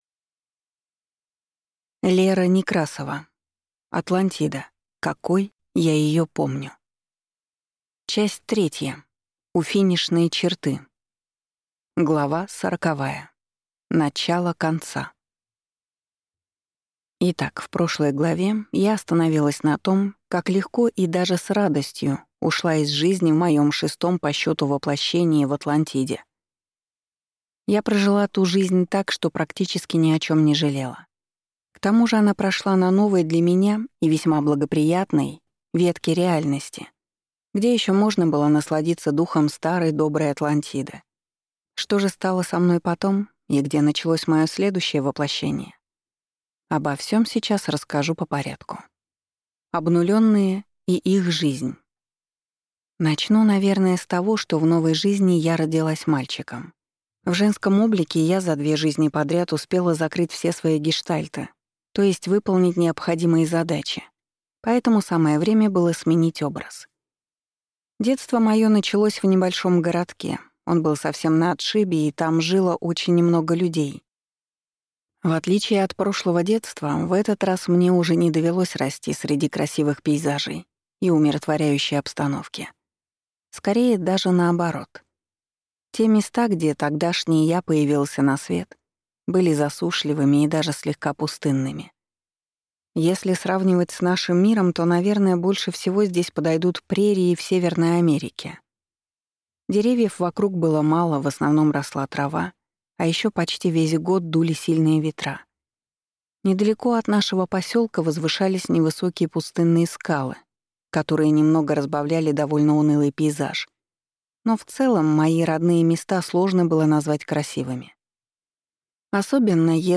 Аудиокнига Атлантида, какой я ее помню… Часть 2 | Библиотека аудиокниг